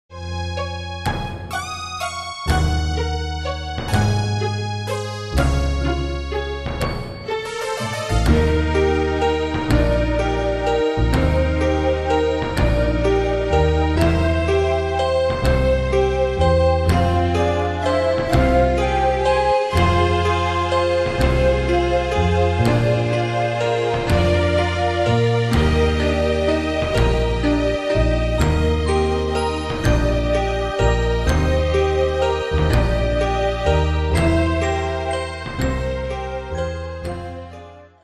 Style: PopFranco Ane/Year: 1988 Tempo: 125 Durée/Time: 4.33
Danse/Dance: Valse/Waltz Cat Id.
Pro Backing Tracks